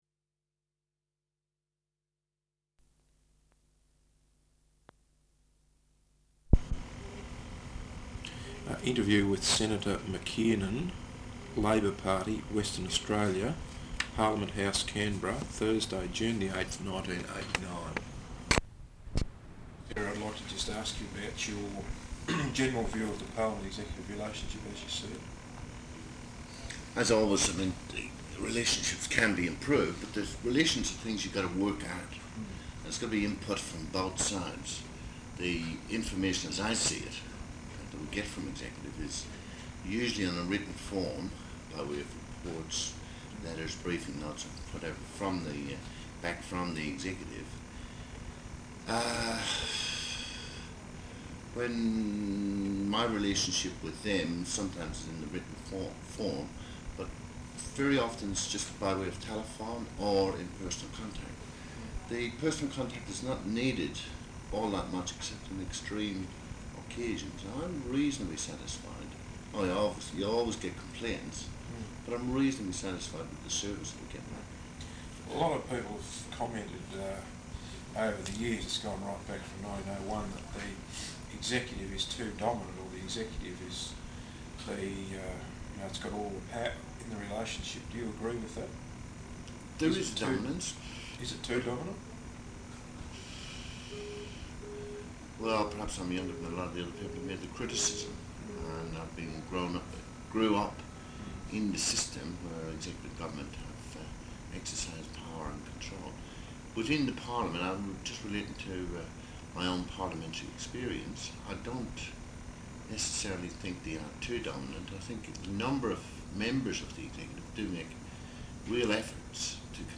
Interview with Senator McKiernan, Labor Party, Western Australia, Parliament House, Canberra, Thursday 8 June, 1989.